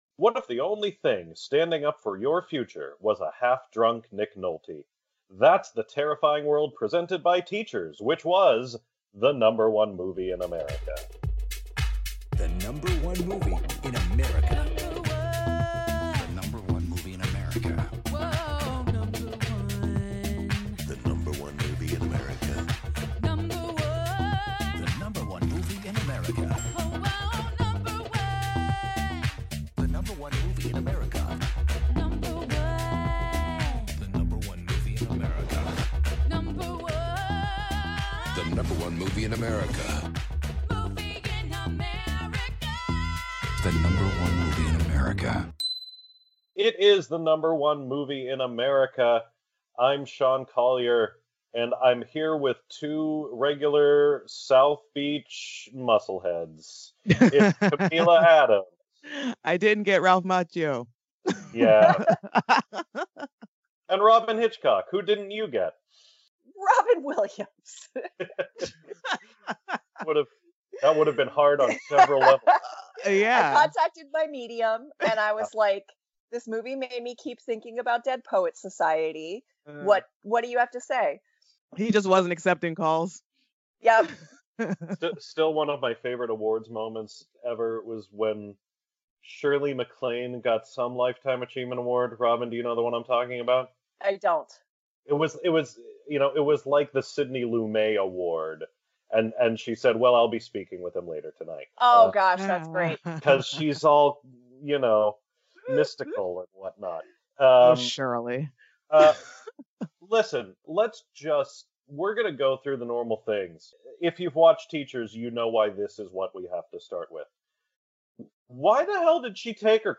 Superfluous nudity, understated violence and more -- including an interview